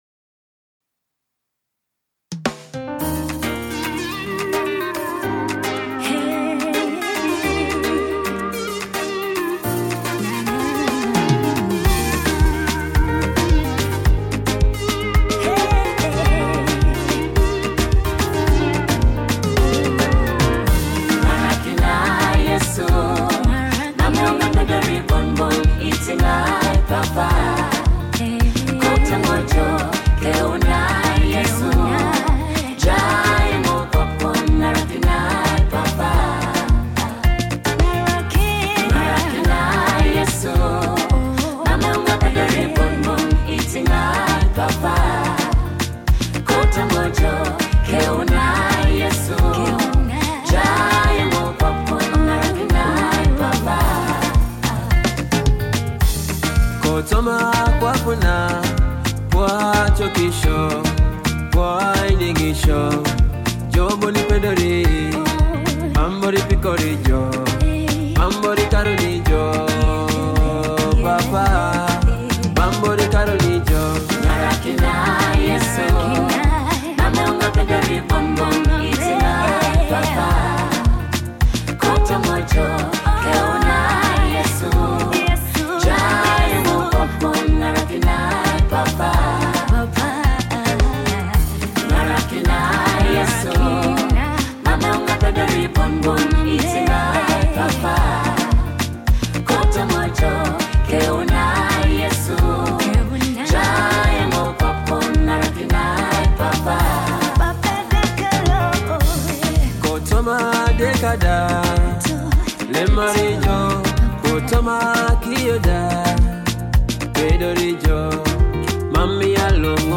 praise and worship
soul-stirring melodies